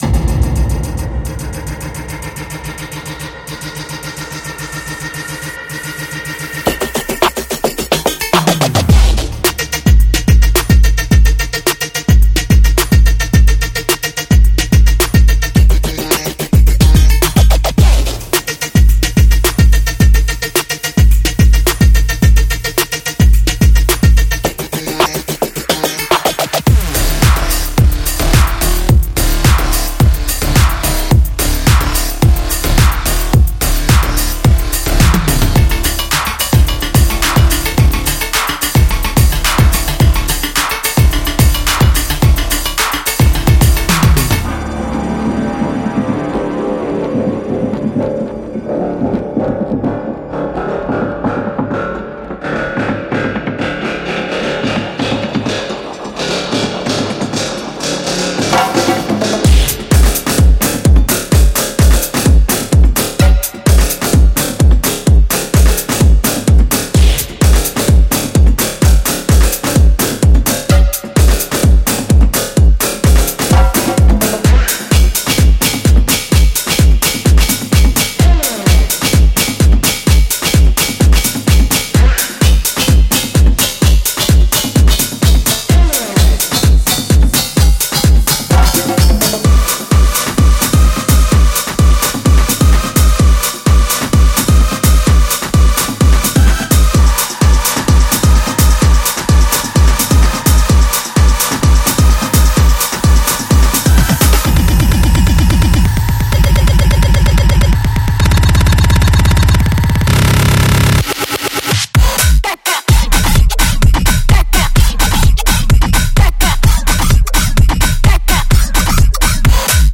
粗大的摇摆声，添加了一些Break Beat元素，并提供了可供下载的完美样本集。
●108- 130 BPM
●44个完整鼓循环，Kick & Snare循环，顶部循环